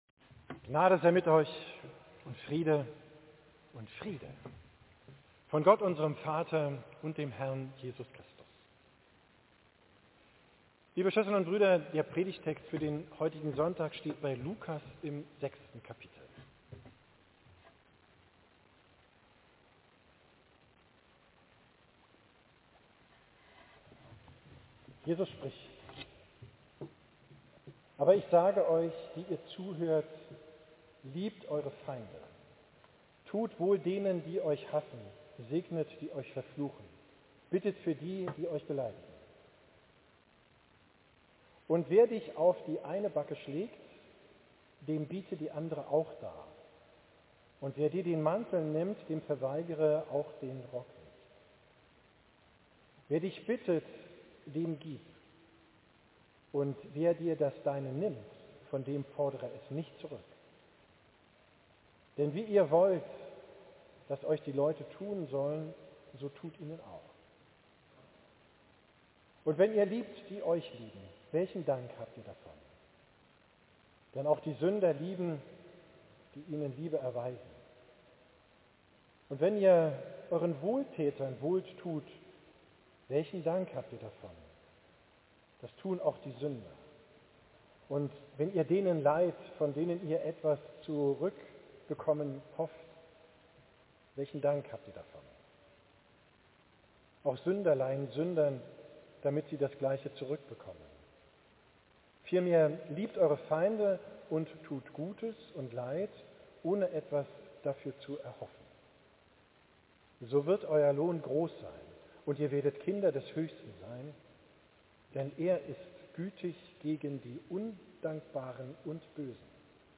Predigt vom drittletzten Sonntag im Kirchenjahr